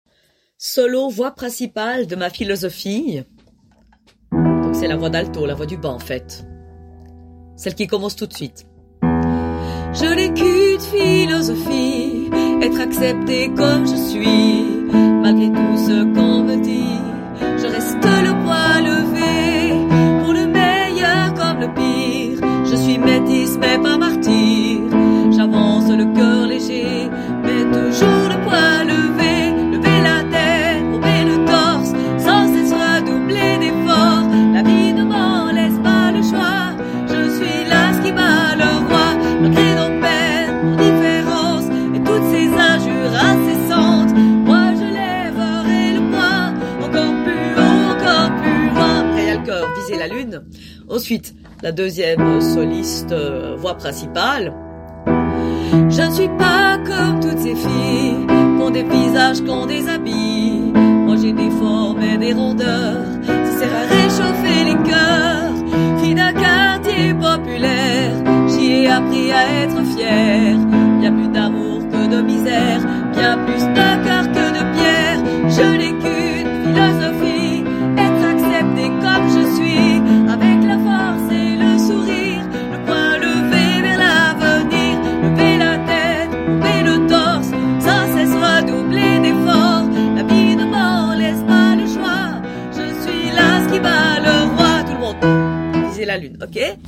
solo voix principale